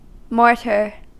Ääntäminen
UK : IPA : /ˈmɔːtɘ(ɹ)/ US : IPA : [ˈmɔːtɘɹ]